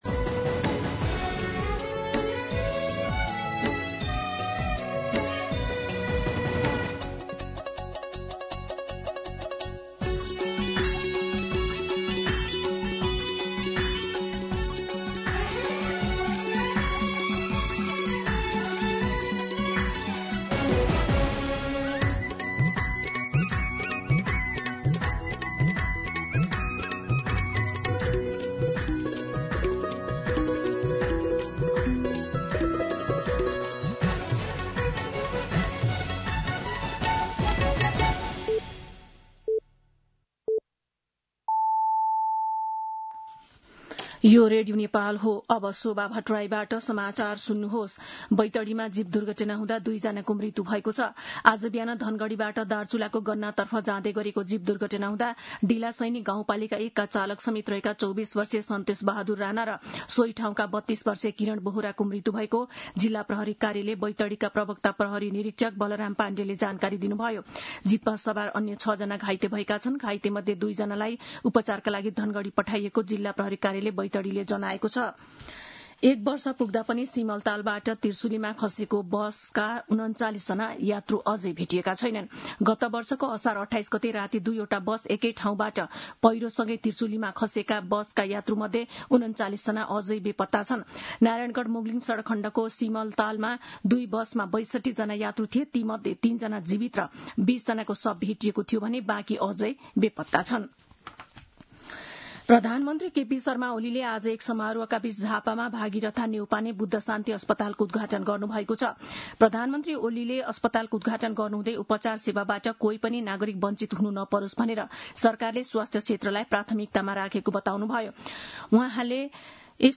4-pm-Nepali-News-1.mp3